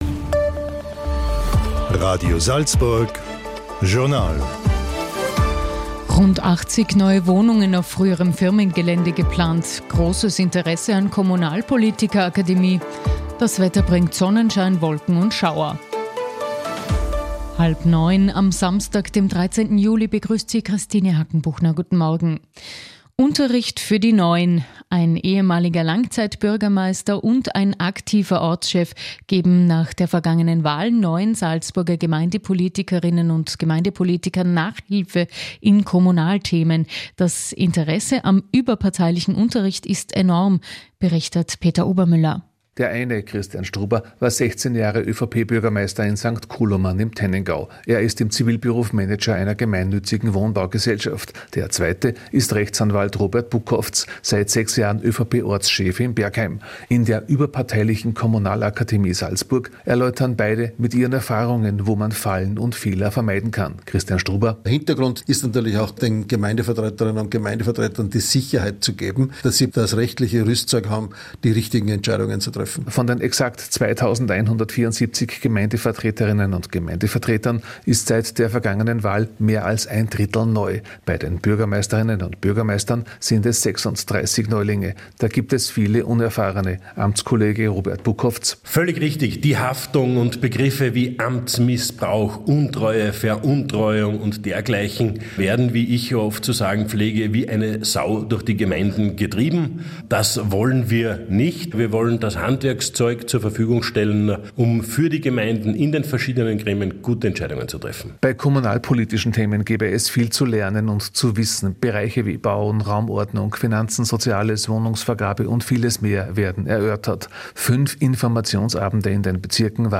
Beitrag von Radio Salzburg über die Kommunal-Akademie Salzburg